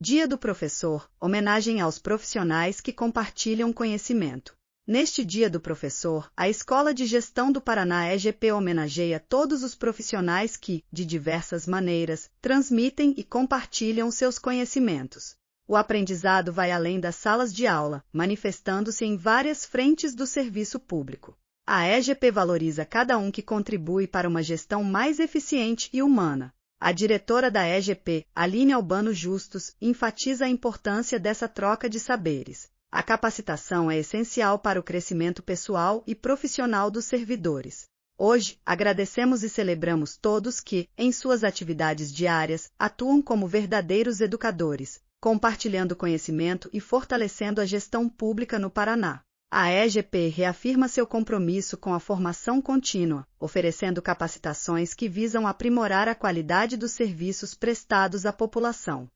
audionoticia_dia_do_professor.mp3